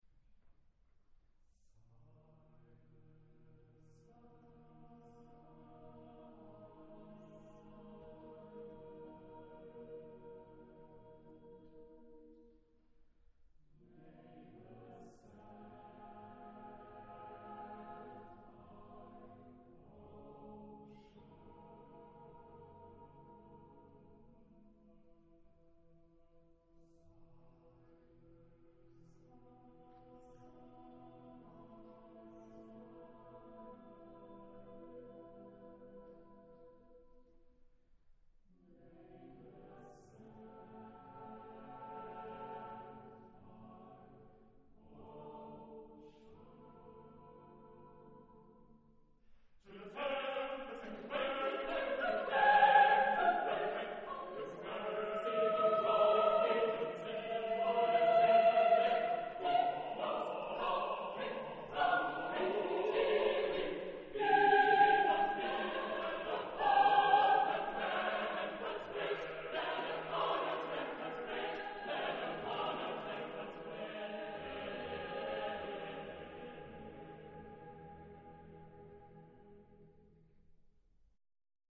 Charakter des Stückes: kontrastreich
Chorgattung: SSAATTBB  (8 gemischter Chor Stimmen )
Tonart(en): Es-Dur
Aufnahme Bestellnummer: Internationaler Kammerchor Wettbewerb Marktoberdorf